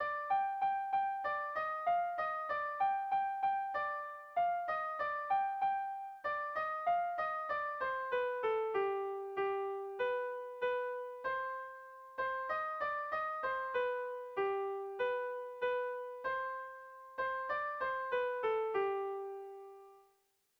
Dantzakoa
A1A2B